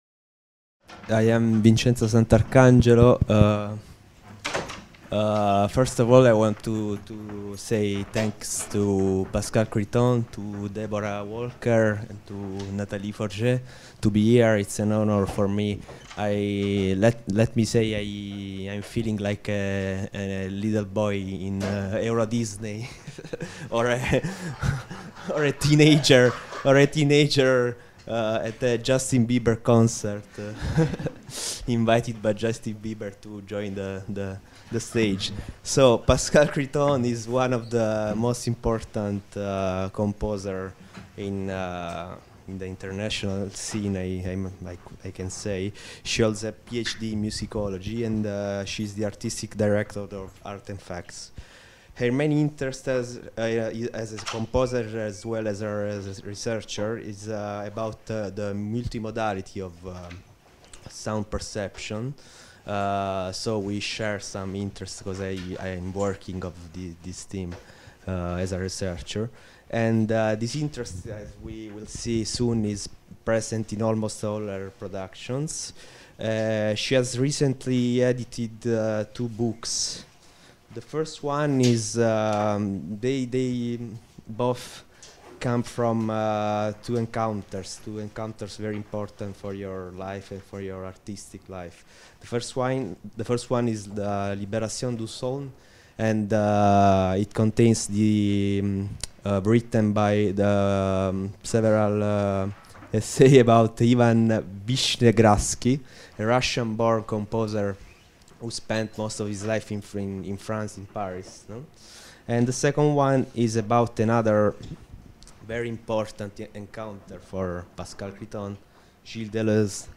for ondes Martenot